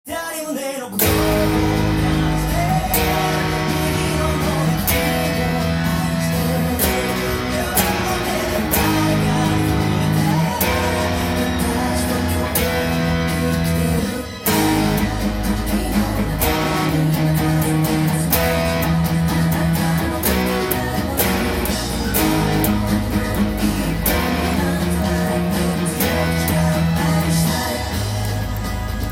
音源にあわせて譜面通り弾いてみました
を殆どパワーコードで弾けるように譜面にしてみました。
ブリッジミュートを使っていきます。
この曲は、テンポも遅くギターで弾きやすいので